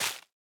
Minecraft Version Minecraft Version 25w18a Latest Release | Latest Snapshot 25w18a / assets / minecraft / sounds / block / big_dripleaf / break3.ogg Compare With Compare With Latest Release | Latest Snapshot